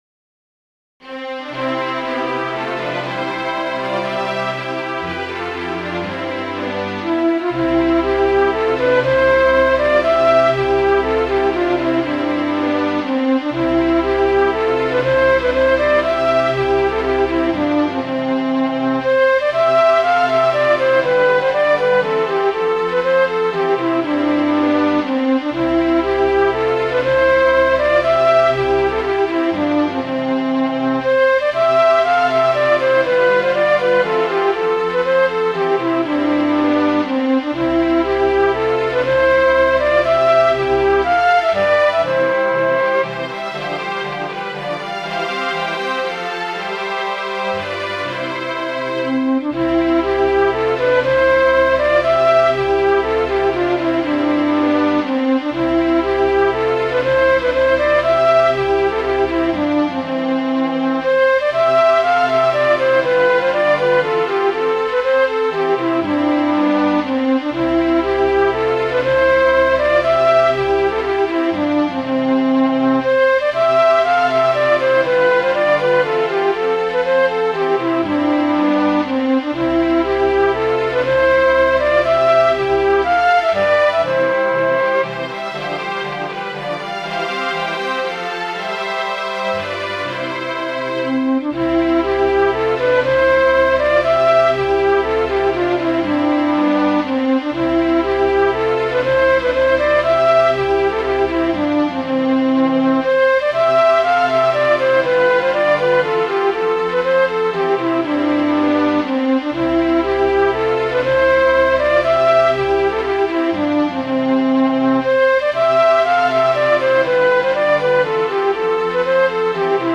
yellow.mid.ogg